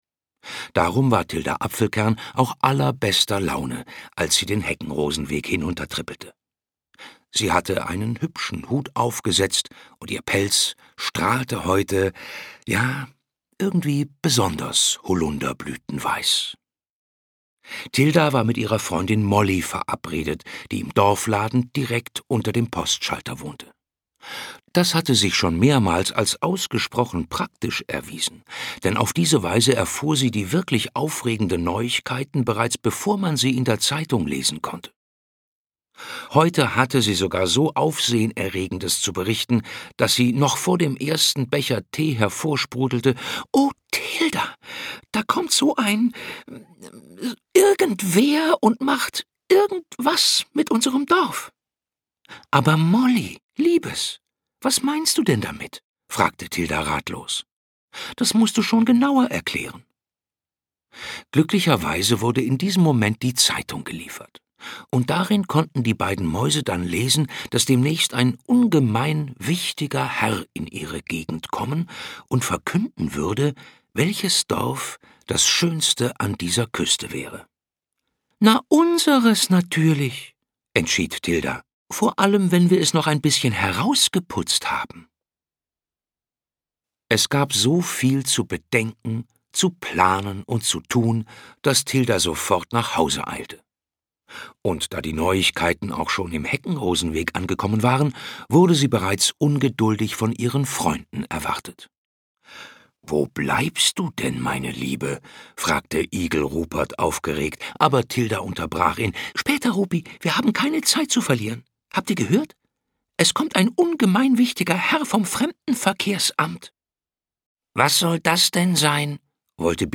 Schlagworte Freundschaft • Hörbuch; Lesung für Kinder/Jugendliche • Kinder/Jugendliche: Natur- & Tiergeschichten • Kinder/Jugendliche: Natur- & Tiergeschichten • Tiergeschichten • Tilda Apfelkern